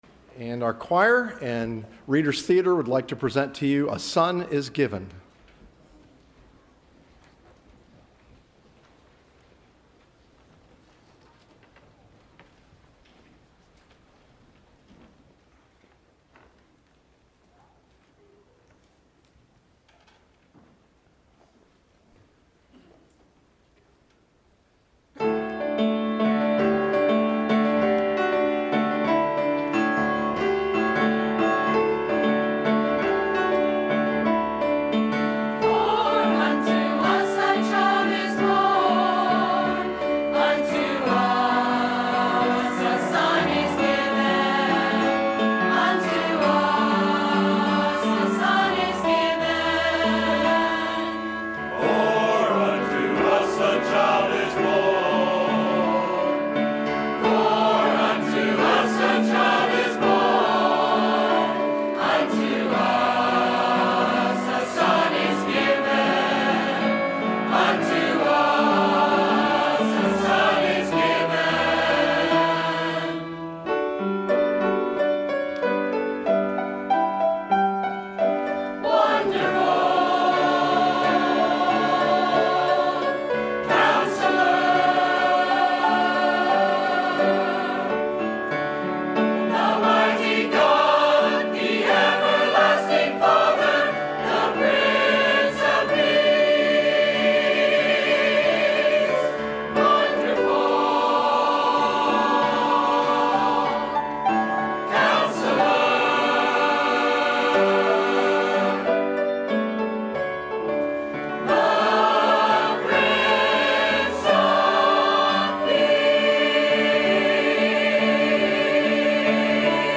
Service Type: Sunday Evening Choir